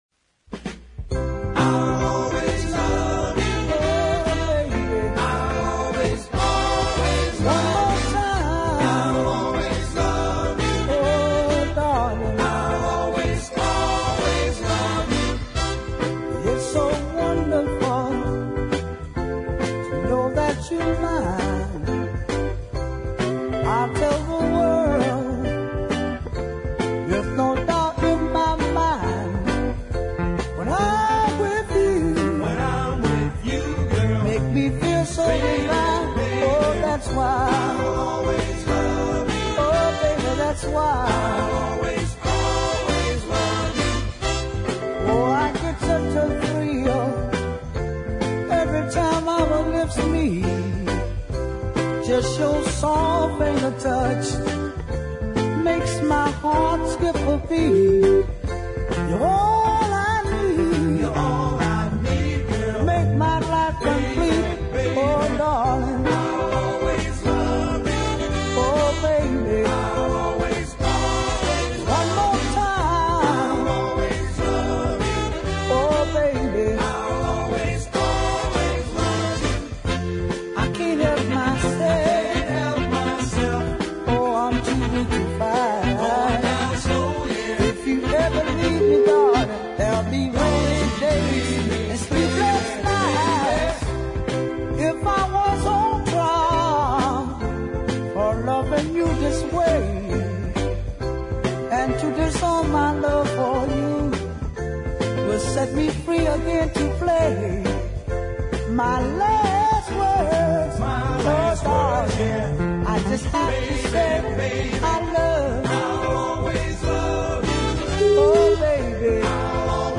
it swings pretty well